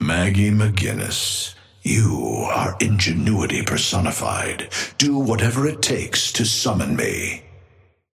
Amber Hand voice line - Maggie McGinnis, you are ingenuity personified.
Patron_male_ally_forge_start_01.mp3